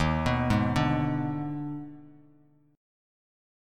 EbM7sus4#5 chord